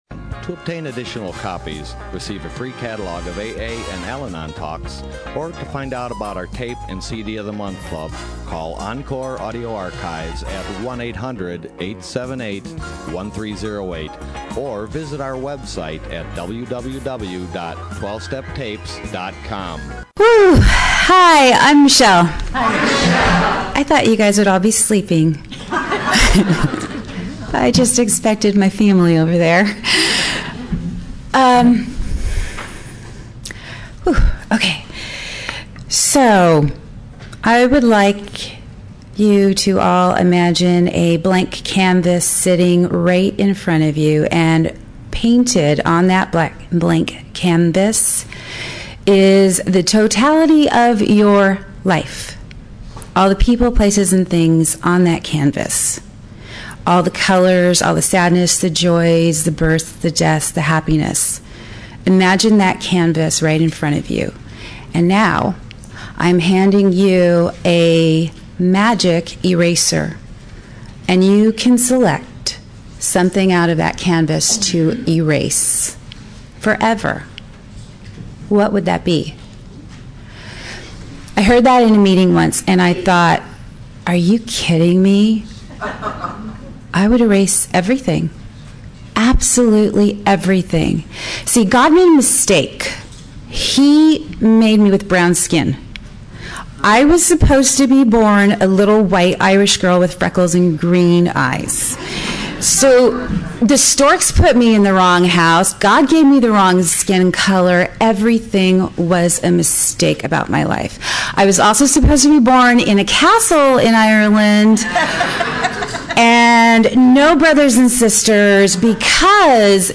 SoCAL AA Convention
AFG Closing Meeting &#8211